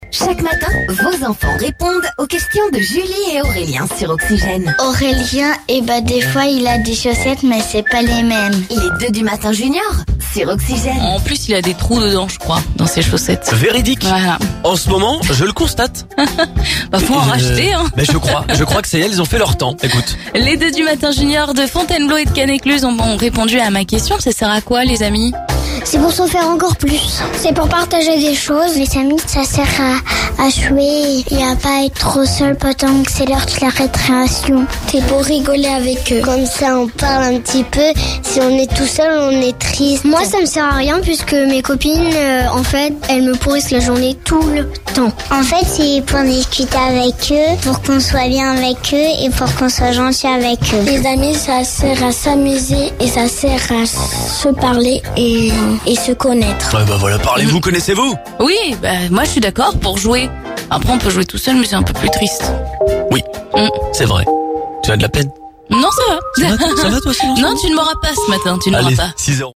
Tous les jours à 06h10, 07h10 et 09h10 retrouvez les 2 du Matin Junior sur Oxygène, la radio de la Seine et Marne !